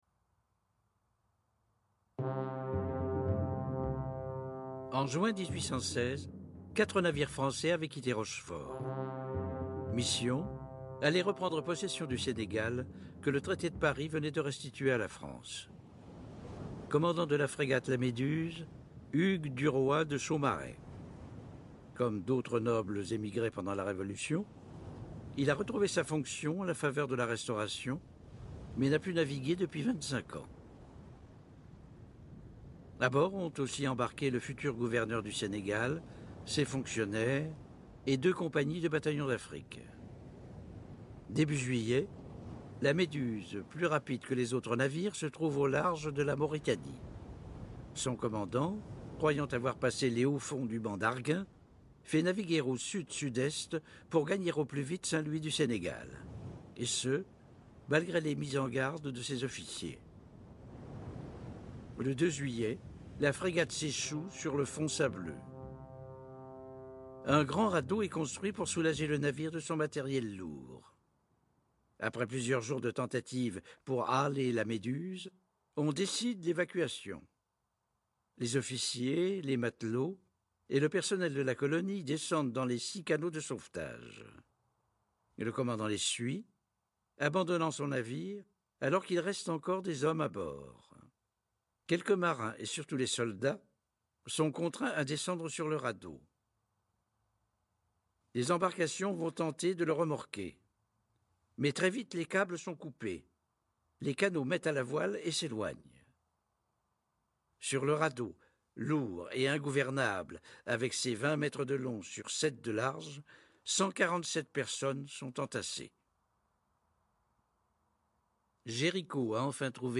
à partir de la voix off de Palette, Marcel Cuvelier: Le radeau de la méduse de Géricault
Ce dispositif marche avec des extraits du discours de Marcel Cuvelier (voix off des émissions Palettes)